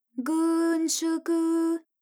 ALYS-DB-002-JPN - Source files of ALYS’ first publicly available Japanese vocal library, initially made for Alter/Ego.